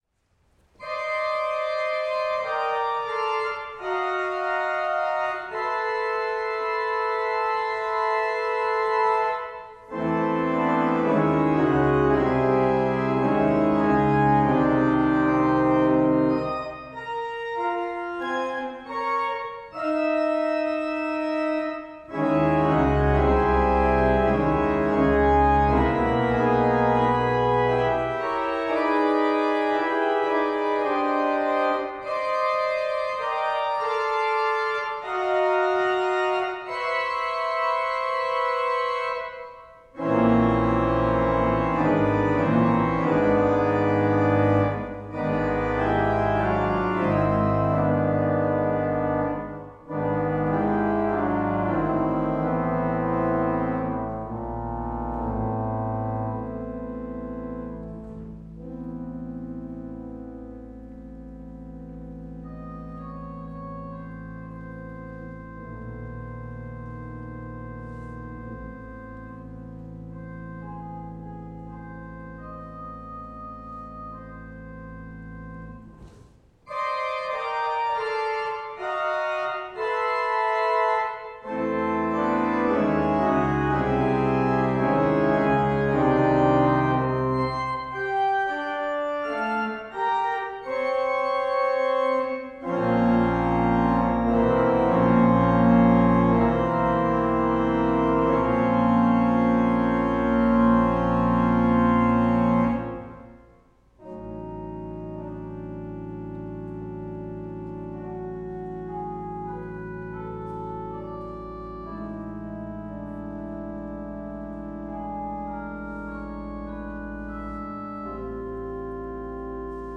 Brookline High School Camerata
Sunday, April 10, 2011 • United Parish, Brookline, MA